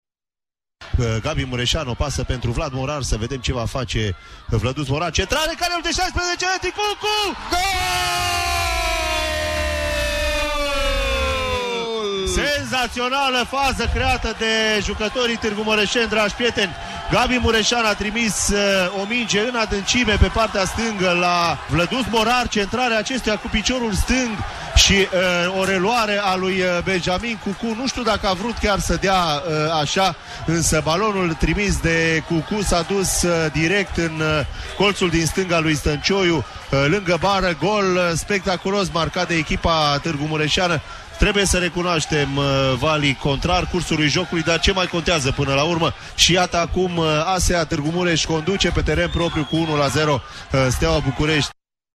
Radio România 88- Transmisiuni sportive, momente remarcabile
01-nov-Secvente-Meci-ASA-STEAUA.mp3